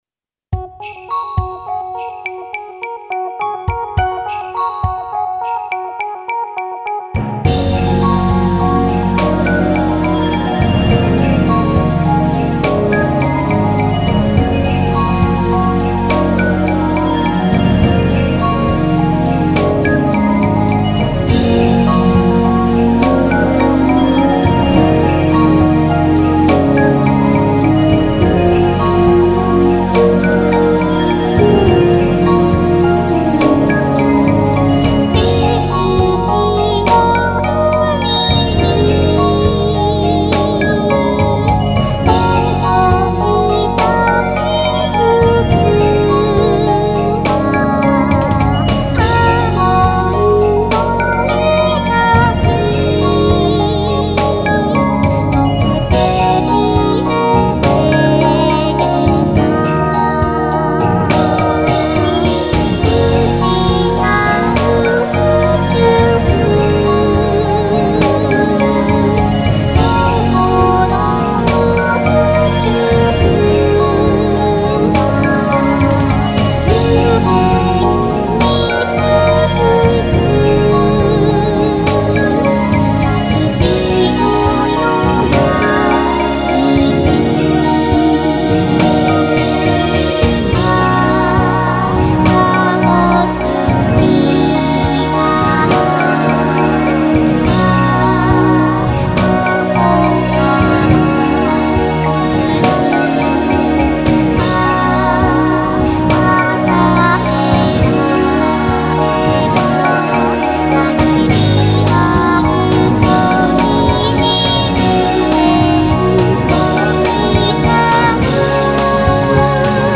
「心の宇宙」をテーマにした壮大な曲です。